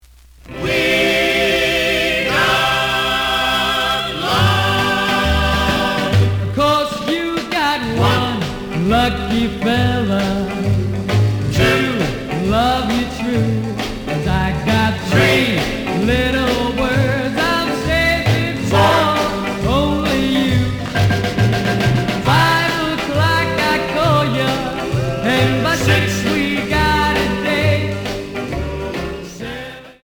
試聴は実際のレコードから録音しています。
●Genre: Rhythm And Blues / Rock 'n' Roll
●Record Grading: VG (両面のラベルにダメージ。)